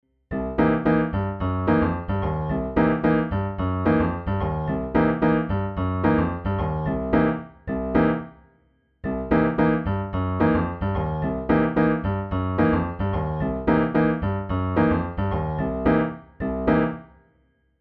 With that in mind, a good way to add the right hand to the left-hand bassline is playing in the spaces of the groove. The only accent with both hands together is on the 1 and of each bar plus the 3 of bar 4.
The right hand plays short attacks of a C7 chord with a sharp ninth, a typical funk chord.
C7#9 with the root C, the 3rd E, the minor 7th Bb and the sharp 9th Eb (or D#)
funk-bassline-riff.mp3